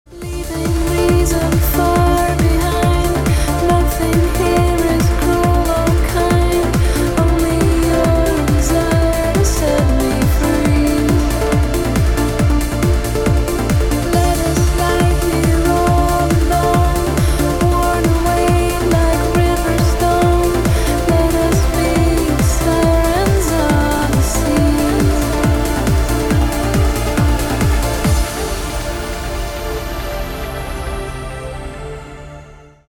• Качество: 256, Stereo